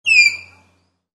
На этой странице собраны разнообразные звуки тапиров — от нежного похрюкивания до громкого рычания.
Крик тапира в ночи